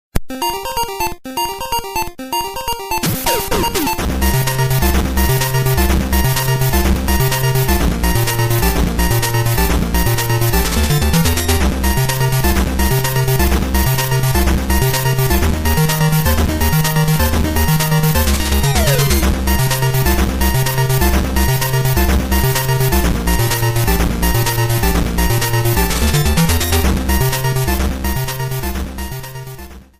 Game select's theme
Fair use music sample